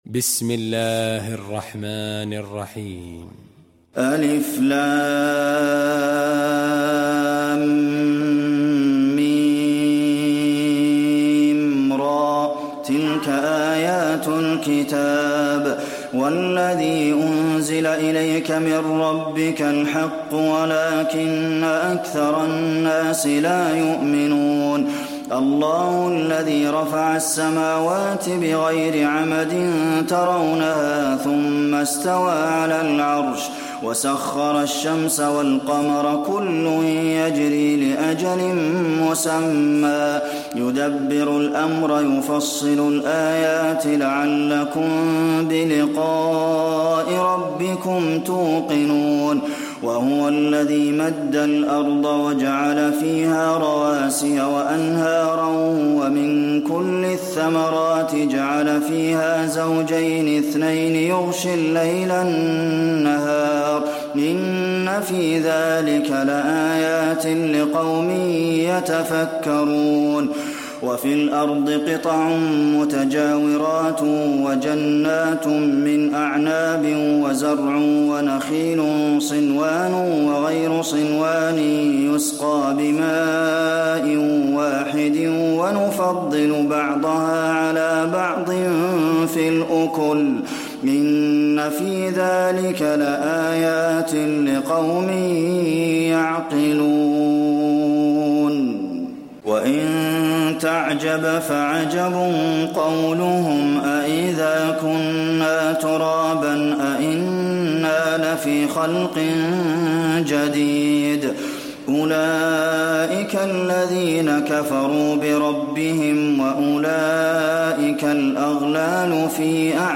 المكان: المسجد النبوي الرعد